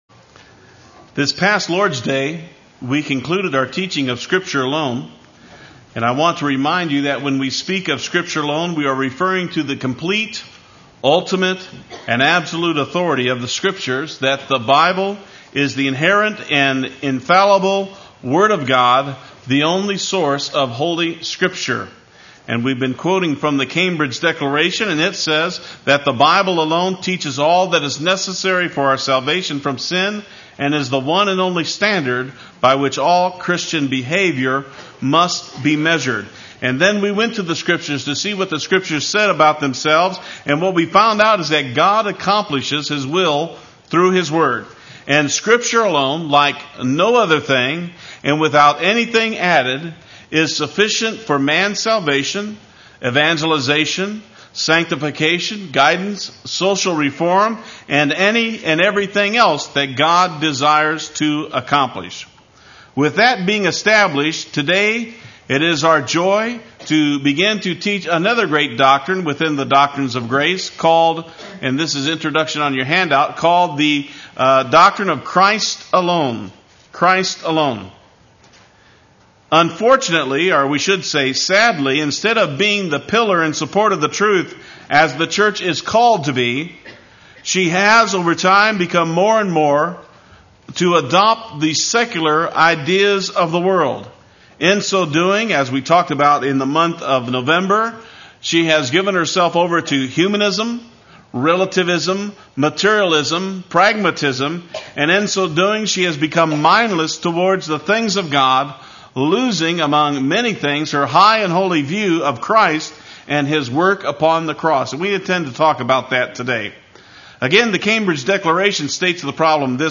Play Sermon Get HCF Teaching Automatically.
Christ Alone Part I Sunday Worship